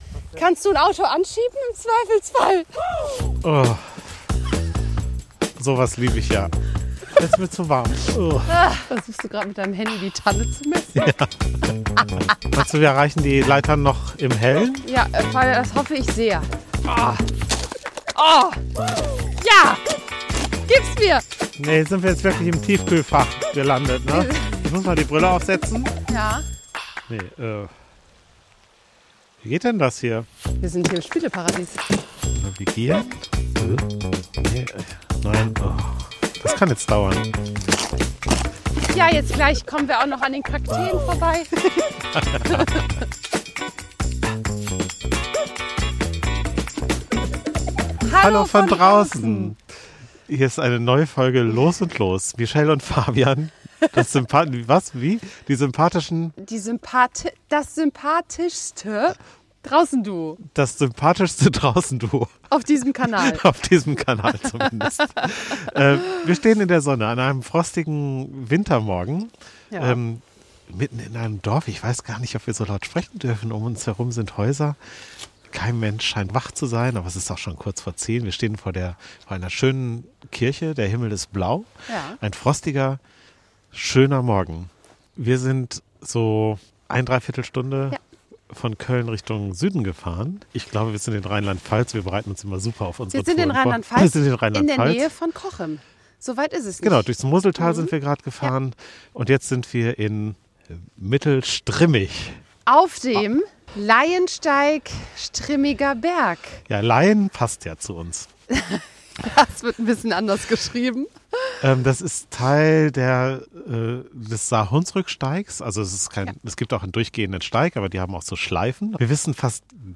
Für unsere zweite Folge sind wir knapp 2 Stunden an einem sonnigen, kalten Dezembermorgen in den Hunsrück gefahren.